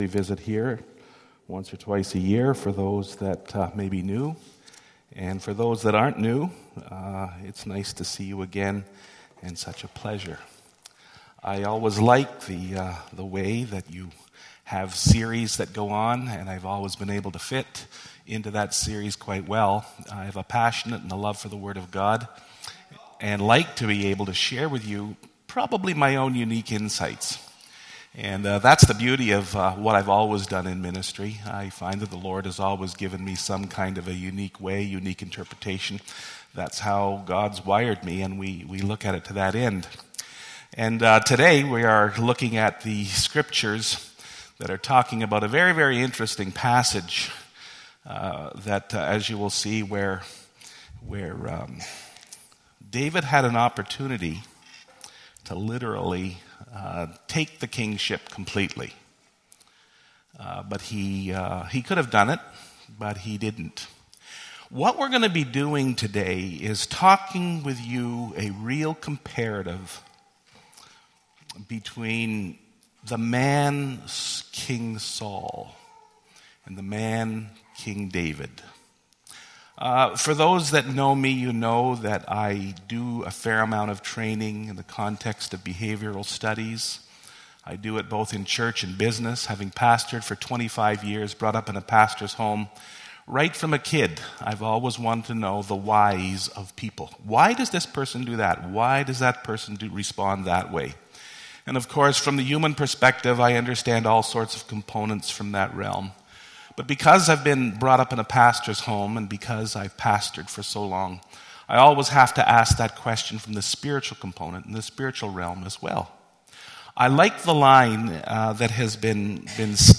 This sermon is based on 1 Samuel 24.